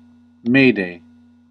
En-us-mayday.ogg